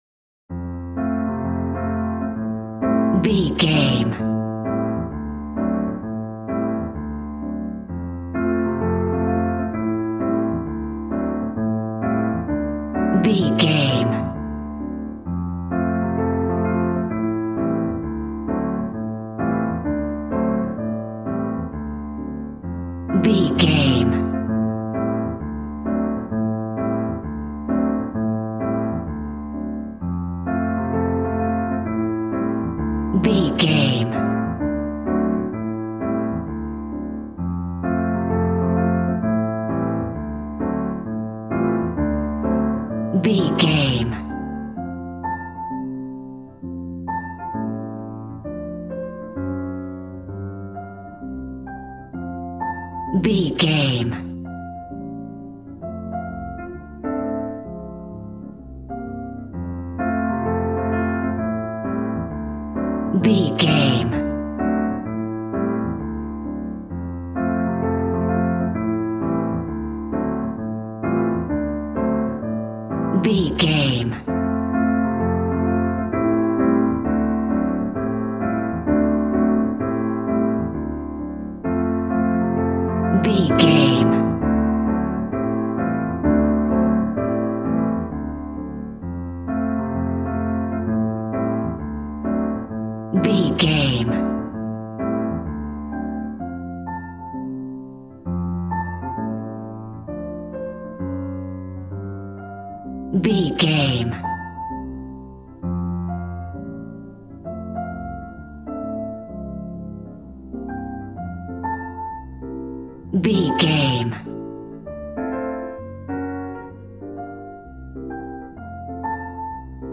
Aeolian/Minor